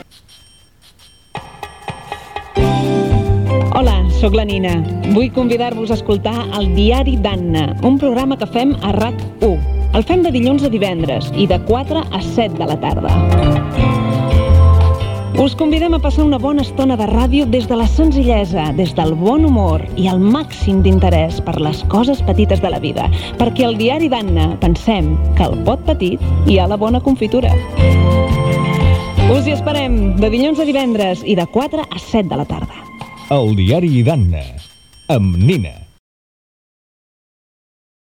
Falca promocional del programa